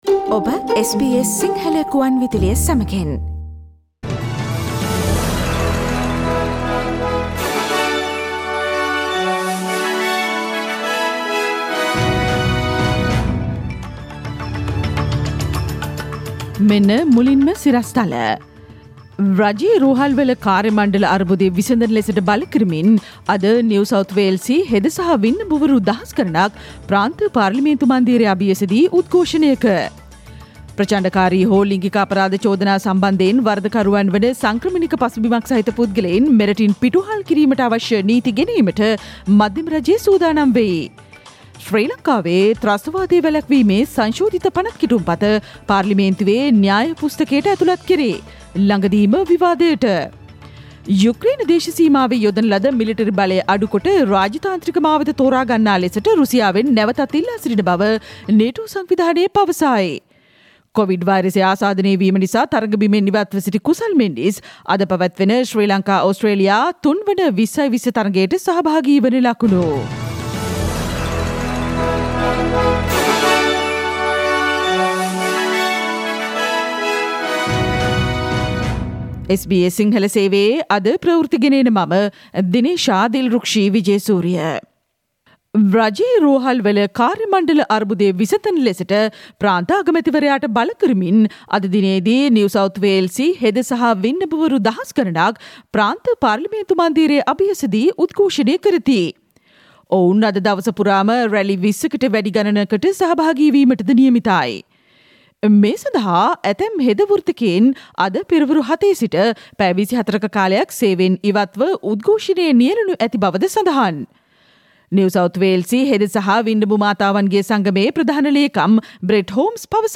පෙබරවාරි 15 වන අඟහරුවාදා SBS සිංහල ගුවන්විදුලි වැඩසටහනේ ප්‍රවෘත්ති ප්‍රකාශයට සවන්දෙන්න ඉහත චායාරූපය මත ඇති speaker සලකුණ මත click කරන්න